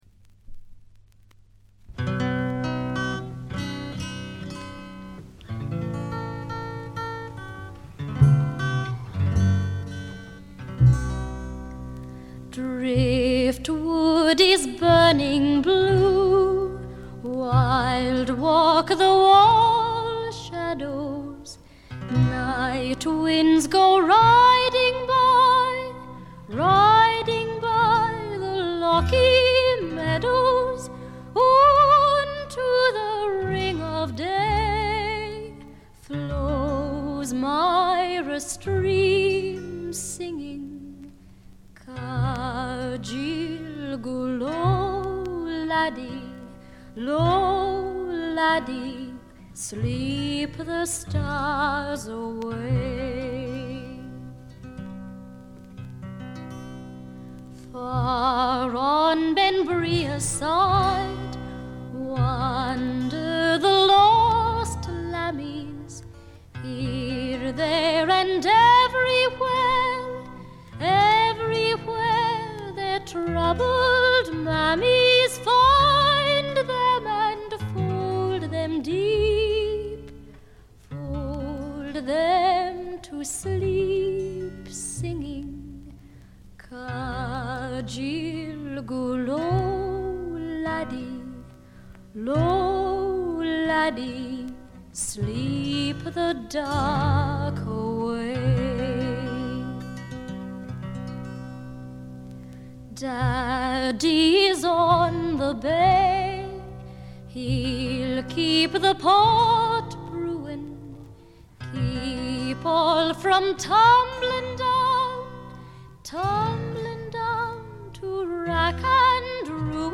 わずかなチリプチ程度。
カナダの女優／歌姫による美しいフォーク作品です。
この時点でまだ20歳かそこらで、少女らしさを残したかわいらしい歌唱がとてもよいです。
試聴曲は現品からの取り込み音源です。